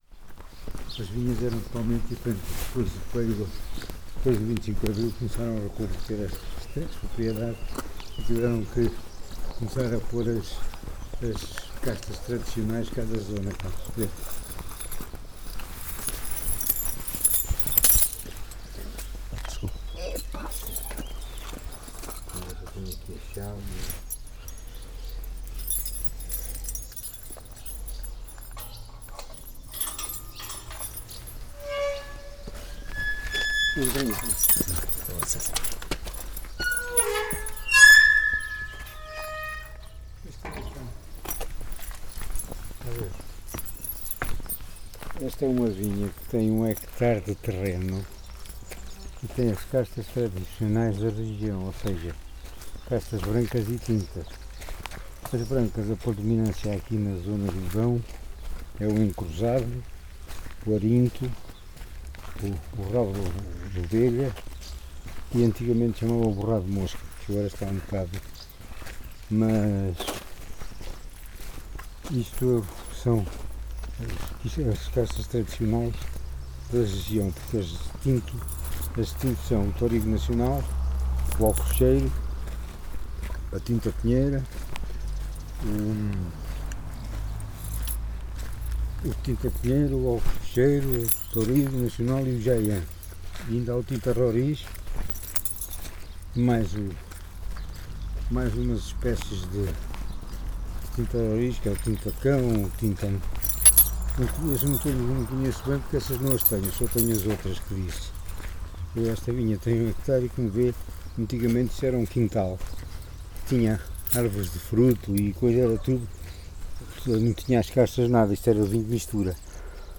Tipo de Prática: Inquérito Etnográfico
Local: Pindelo de Silgueiros